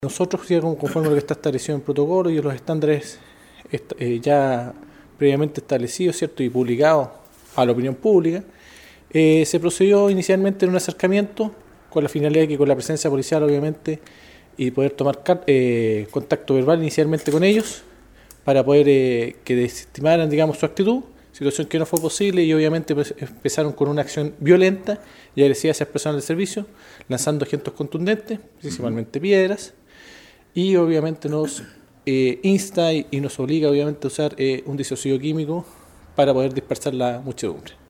El oficial de Carabineros detalló la operación de disuasión que llevaron a efecto la noche del lunes.